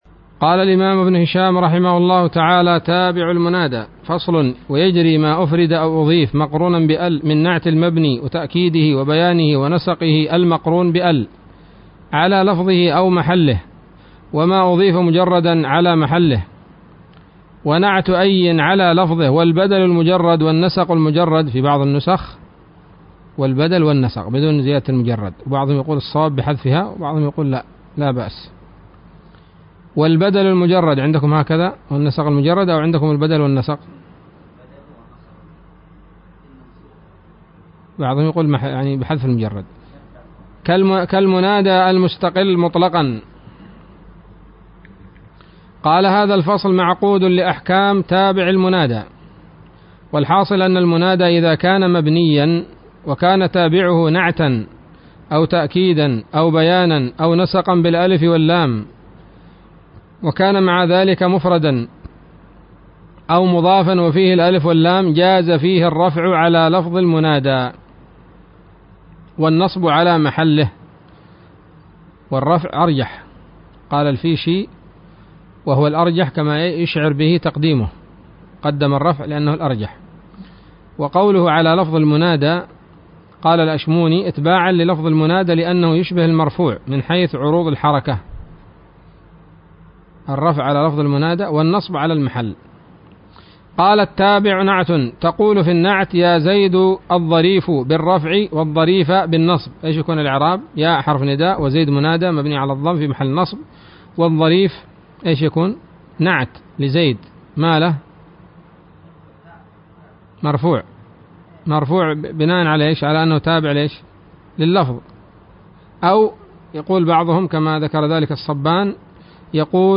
الدرس السابع والثمانون من شرح قطر الندى وبل الصدى